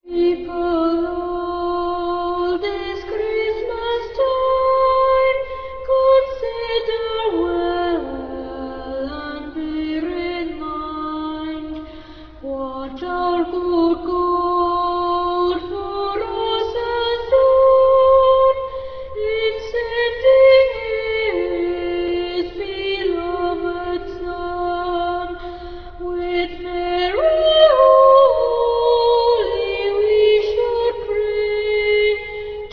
boy soprano